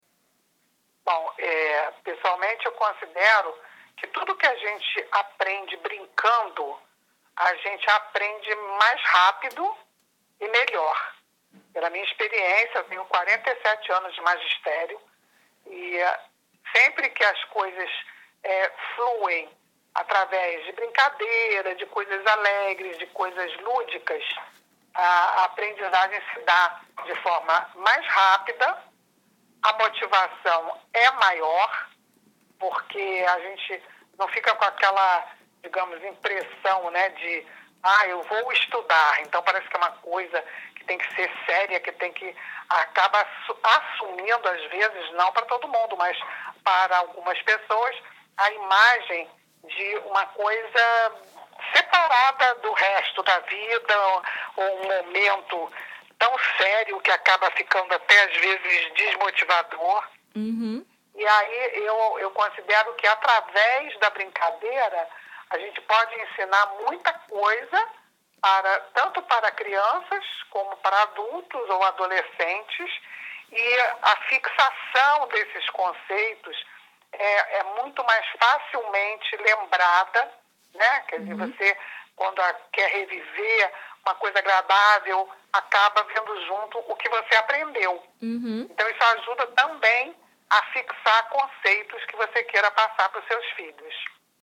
Entrevista especial com Tania Zagury- FOTO TANIA